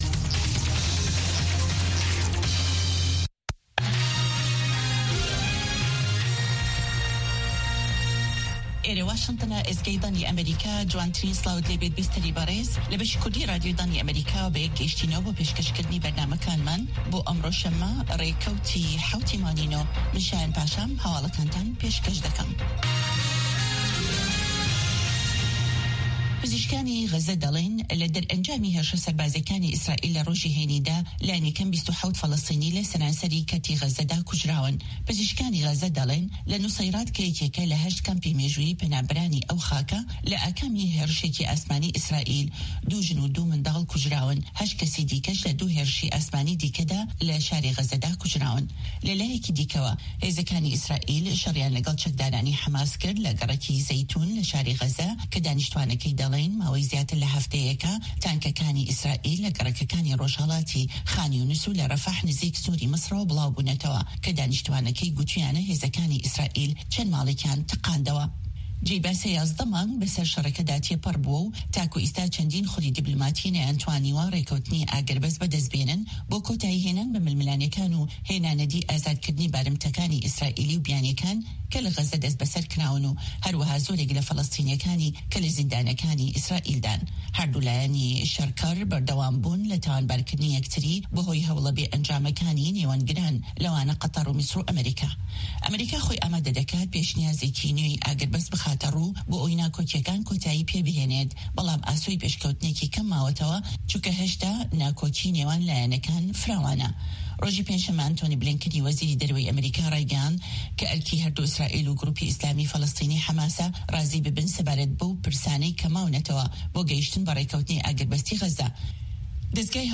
Nûçeyên Cîhanê 1
Nûçeyên Cîhanê ji Dengê Amerîka